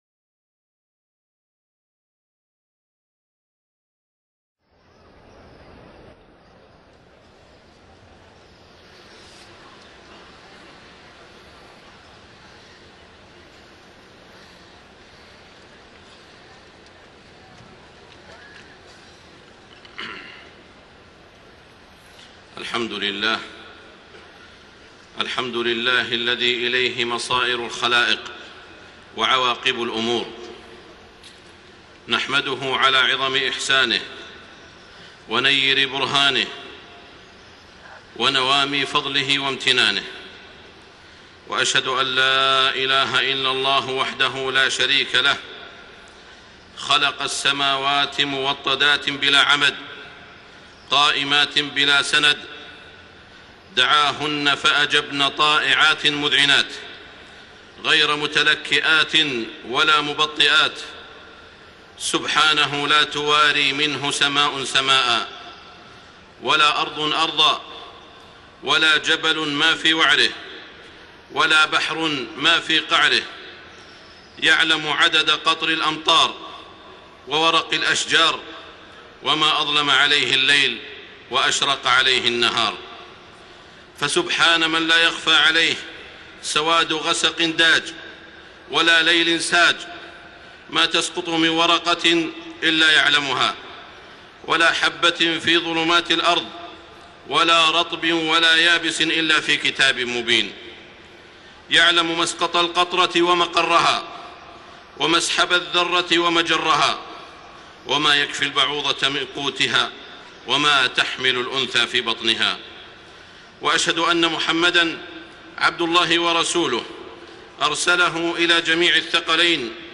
خطبة الإستسقاء 2 ذو الحجة 1431هـ > خطب الاستسقاء 🕋 > المزيد - تلاوات الحرمين